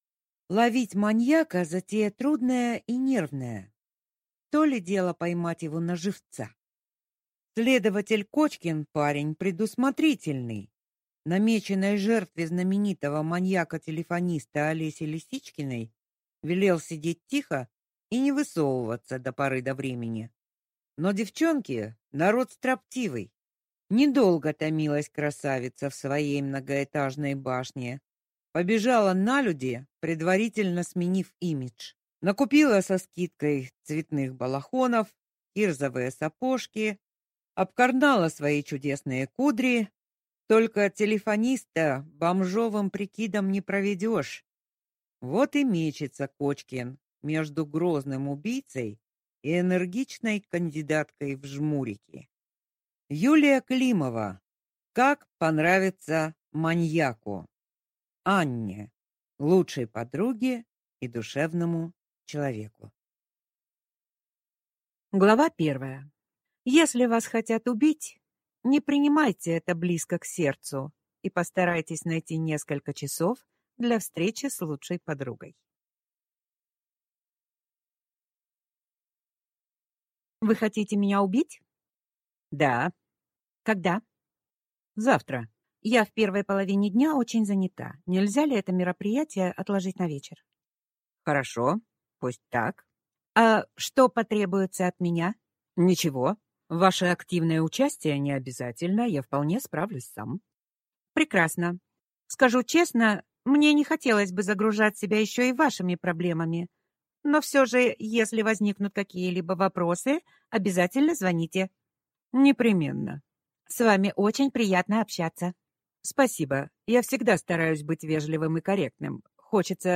Аудиокнига Как понравиться маньяку | Библиотека аудиокниг